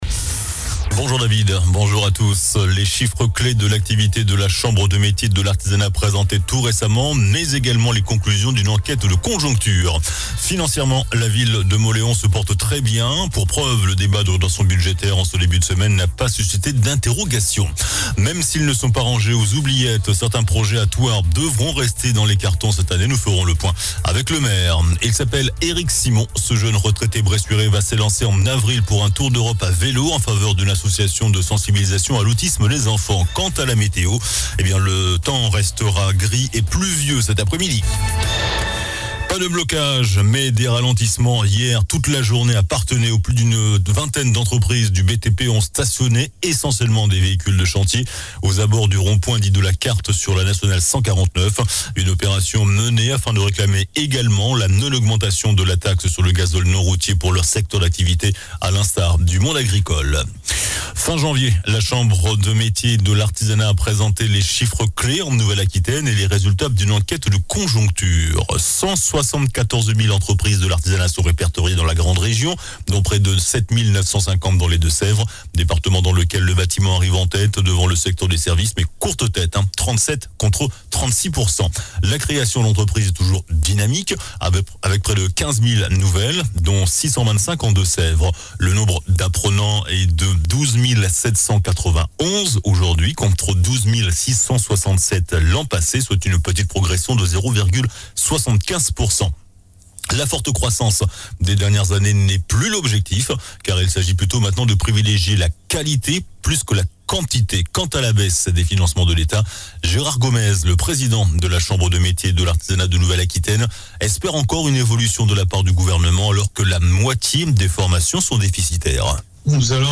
JOURNAL DU MERCREDI 07 FEVRIER ( MIDI )